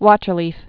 (wôtər-lēf, wŏtər-)